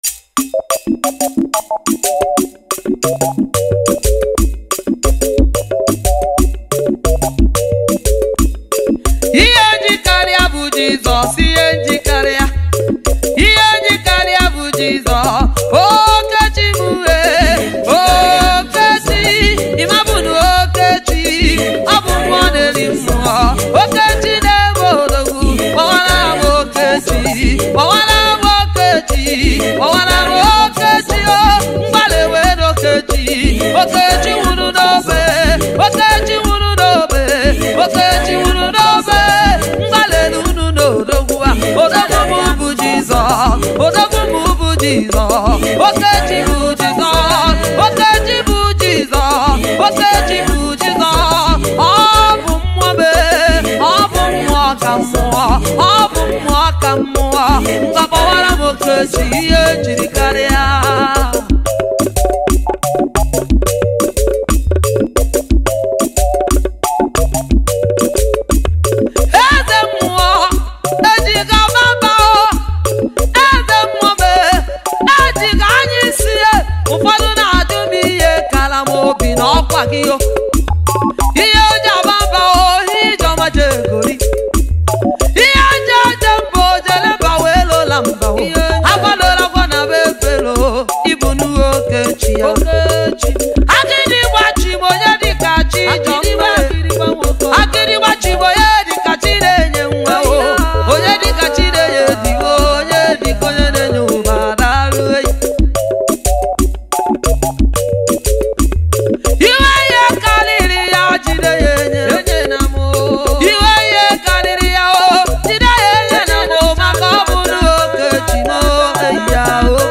February 14, 2025 Publisher 01 Gospel 0
has released a Lively worship.